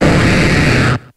Grito de Gyarados.ogg
Grito_de_Gyarados.ogg.mp3